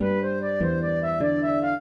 flute-harp